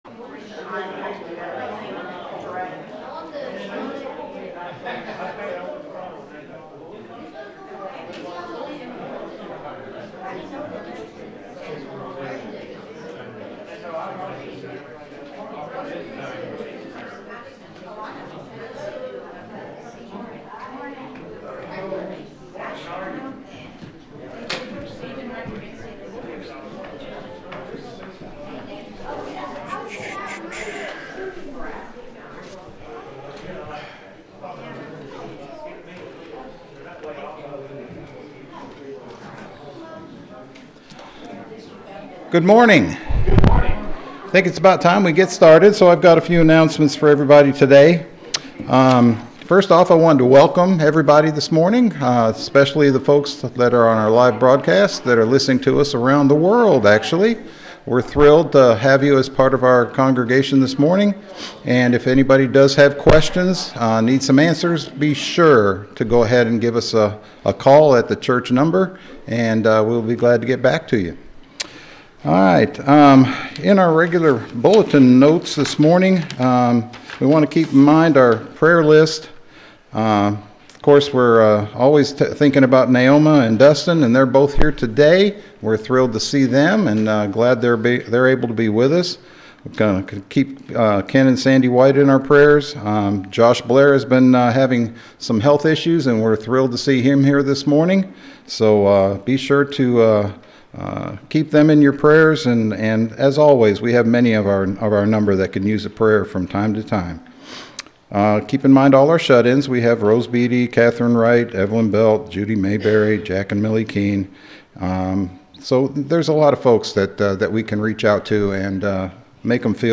The sermon is from our live stream on 2/8/26